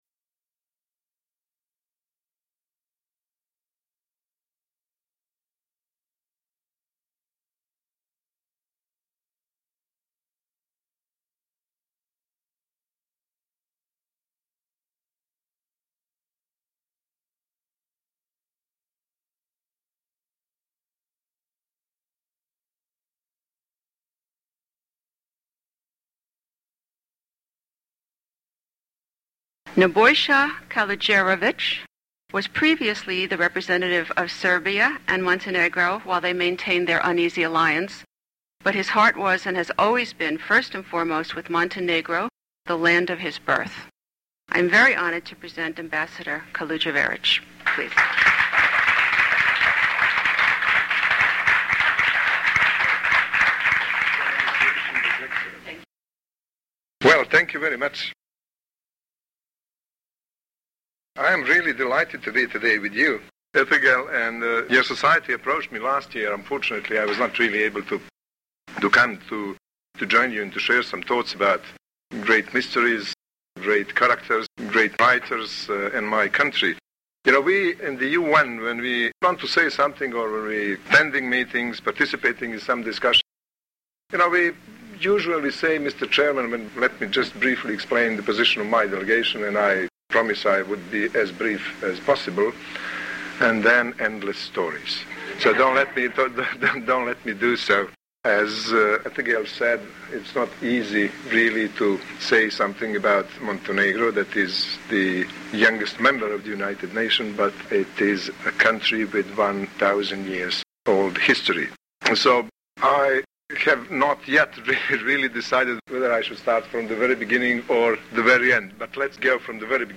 The Montenegrin Ambassador, Nebojsa Kaludjerovic Mr. Kaludjerovic's pronunciation of Montenegrin locations and names is a "must hear"